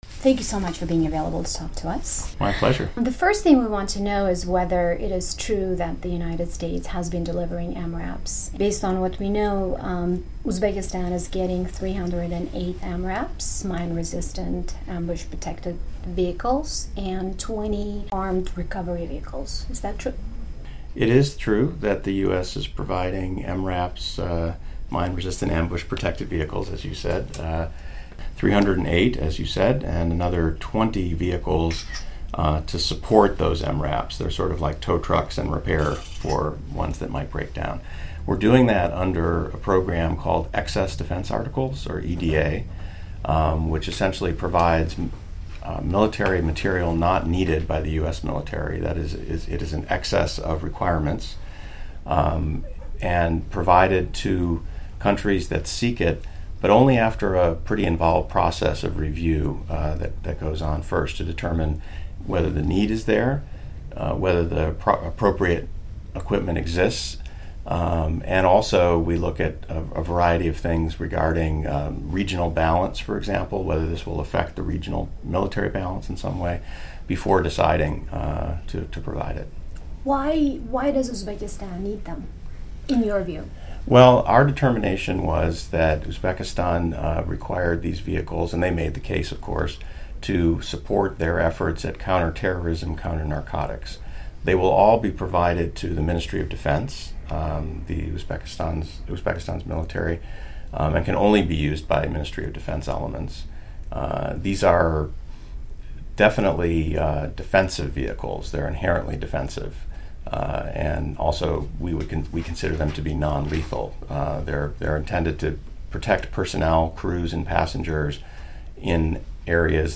US military assistance to Uzbekistan; new strategy for Central Asia; promotion of human rights and democracy, and upcoming elections in Uzbekistan and Tajikistan. Exclusive interview with Daniel Rosenblum, Deputy Assistant Secretary of State for Central Asia.